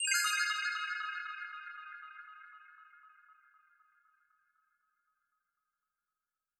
BWB FX FALL (1) (WAKE UP).wav